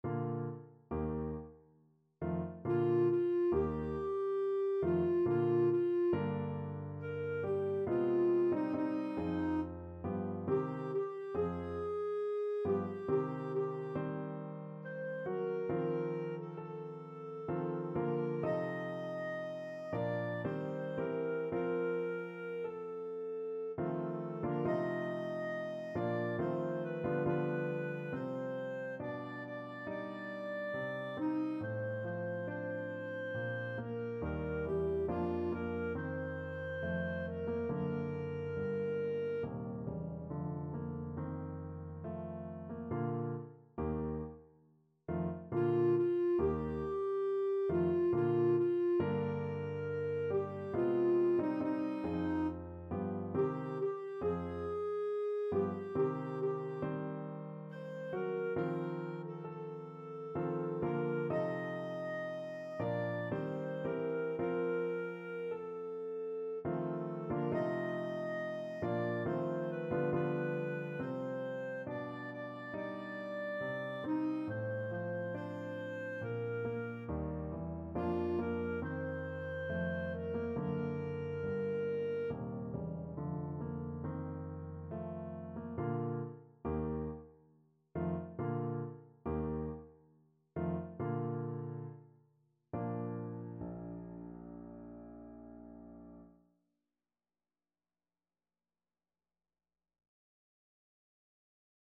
Clarinet version
Eb5-Eb6
3/4 (View more 3/4 Music)
Larghetto =69
Classical (View more Classical Clarinet Music)